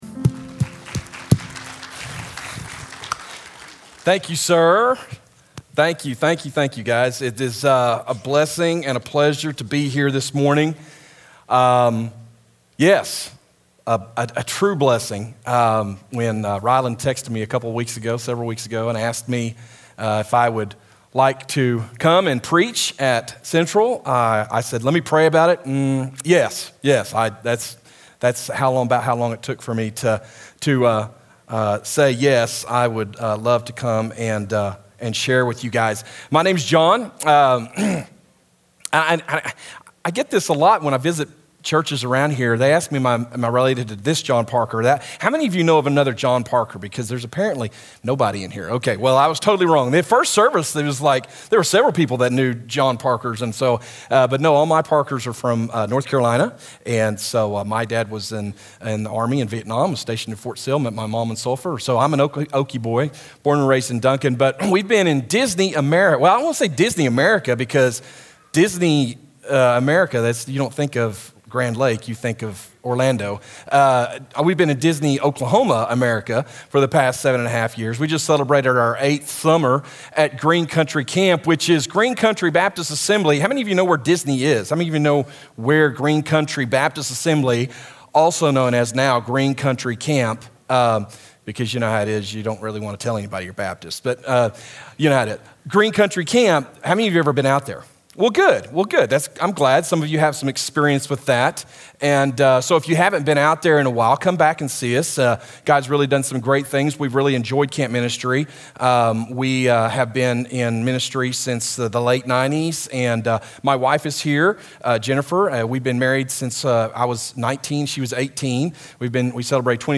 A message from the series "Did He Just Say That?."